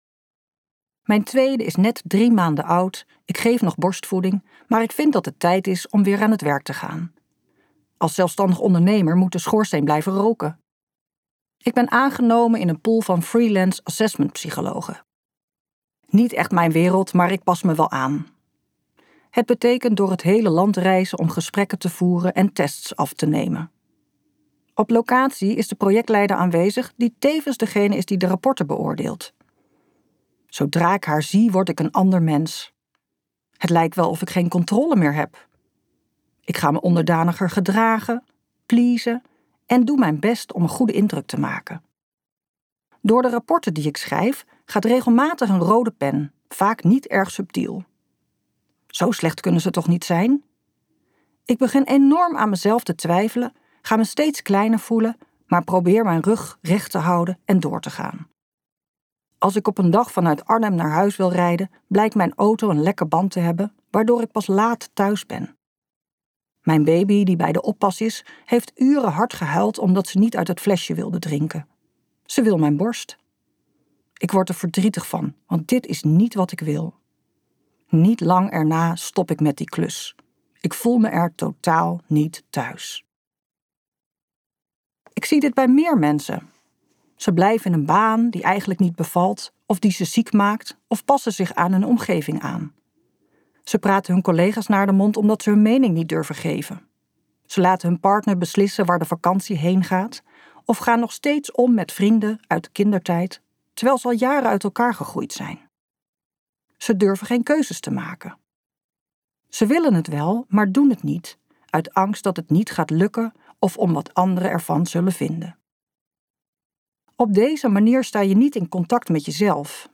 Uitgeverij Ten Have | Thuiskomen bij jezelf luisterboek